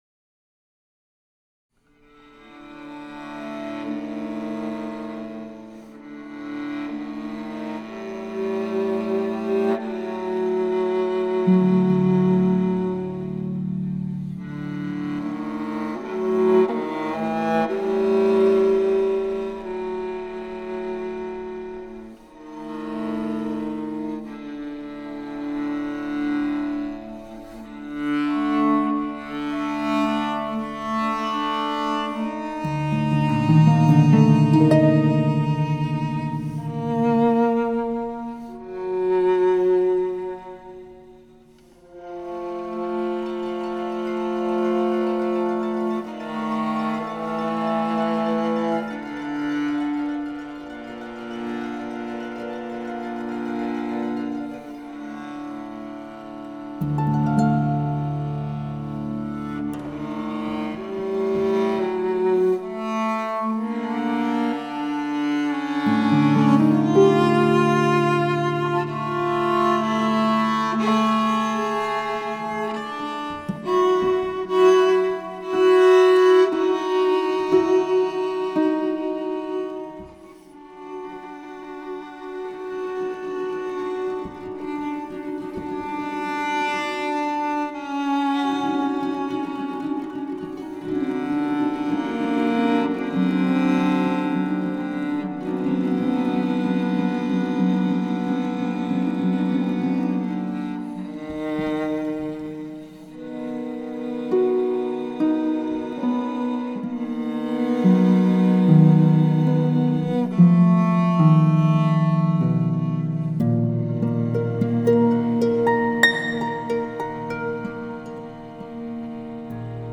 Inst: viola d’amore, harp